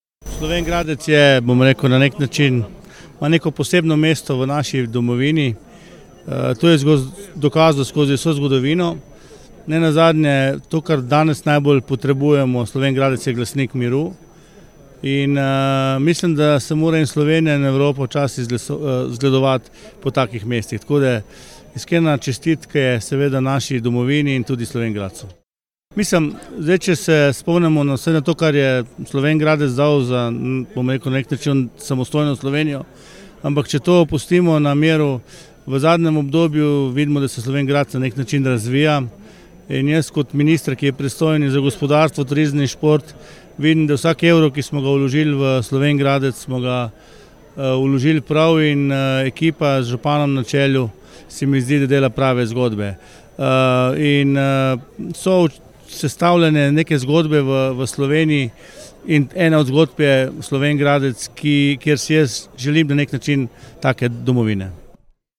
Osrednja proslava ob dnevu državnosti v Slovenj Gradcu
Slavnostni govornik je bil minister za gospodarstvo, turizem in šport Matjaž Han.
izjava Han  ZA SPLET.mp3